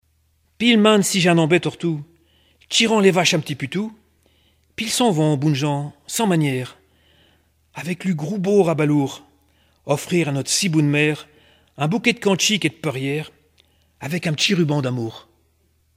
Langue Patois local
Genre poésie
Catégorie Récit